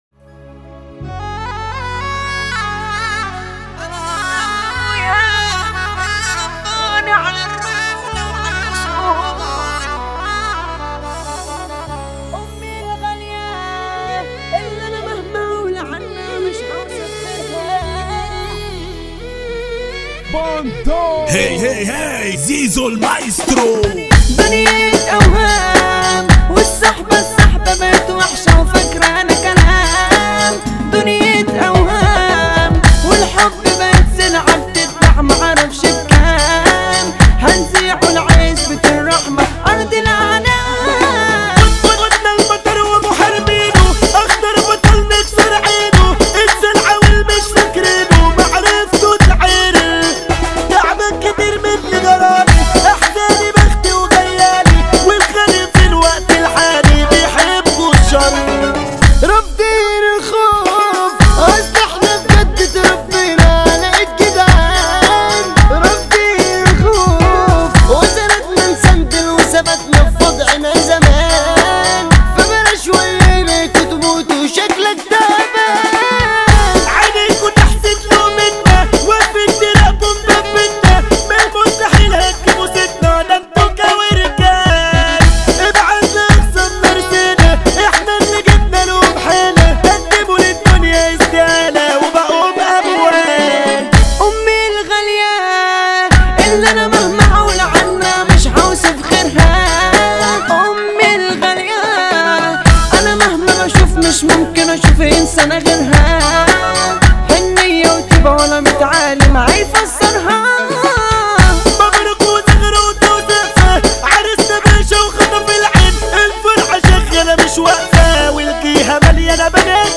اغاني مصرية